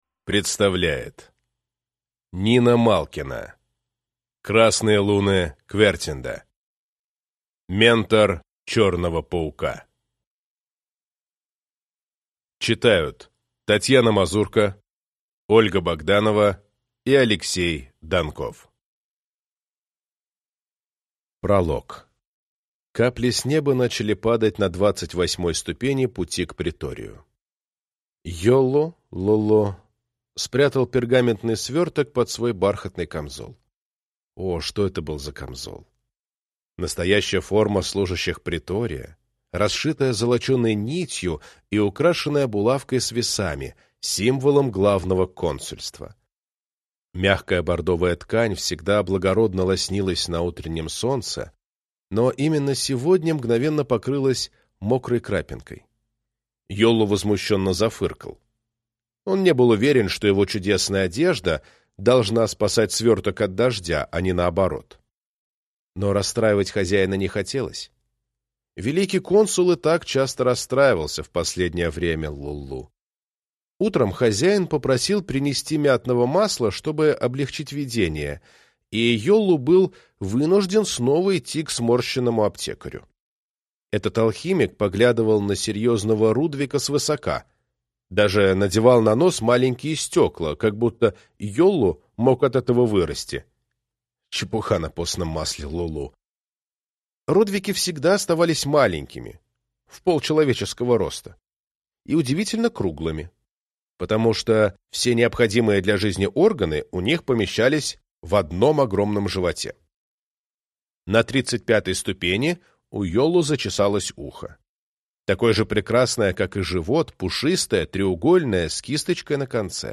Аудиокнига Ментор черного паука | Библиотека аудиокниг